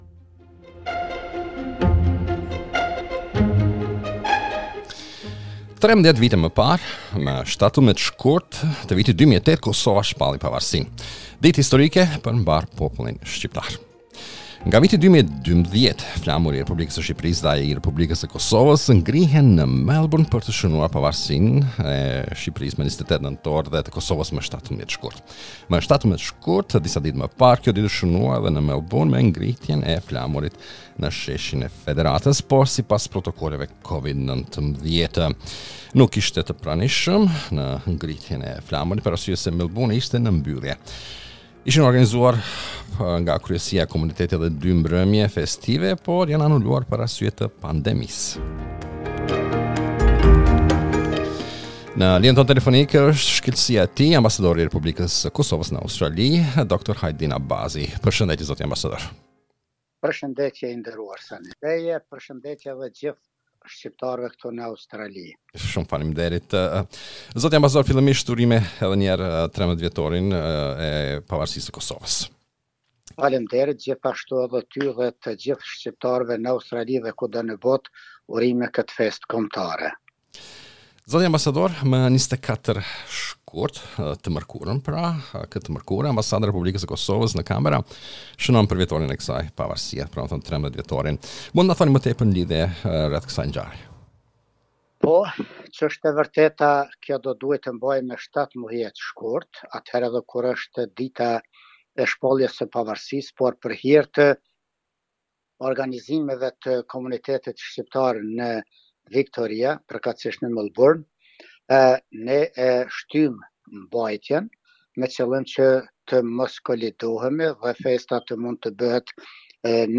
We spoke with the Ambassador of the Republic of Kosovo about the festivities on the 13th Anniversary of the Kosovo Independence as well as about his first visit in New Zealand.